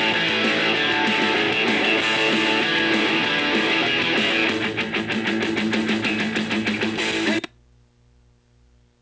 NOISE.WAV